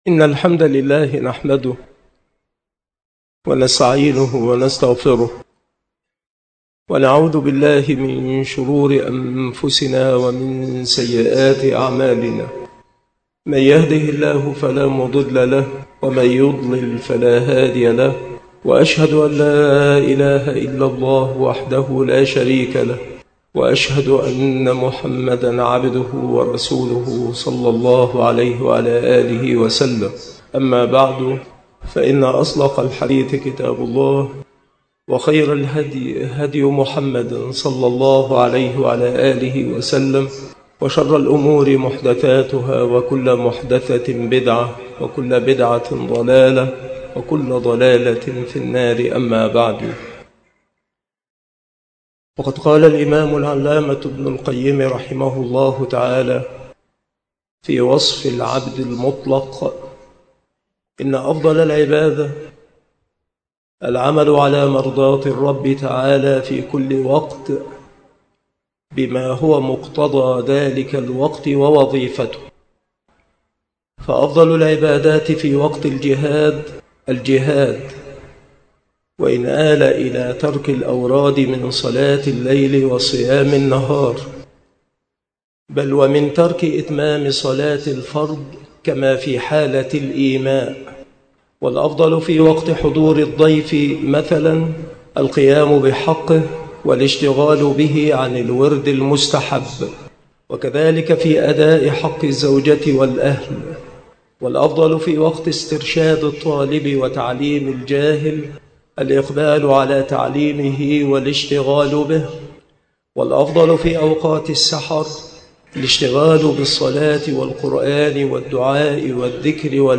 مكان إلقاء هذه المحاضرة بالمسجد الشرقي - سبك الأحد - أشمون - محافظة المنوفية - مصر نبذة مختصرة عن المحاضرة بيان وصف العبد المطلق، والفرق بين أهل التعبد المطلق وأهل التعبد المقيد، مع بيان معنى التوكل، وحقيقته، وذكر أنواعه، ودرجاته، وما قيل فيه، وبيان أن الالتفات إلى الأسباب على ضربين، وذكر نموذج على التوكل على الله مع الأخذ بالأسباب في عصرنا، وهو: إنجاز مشروع قناة السويس الجديدة.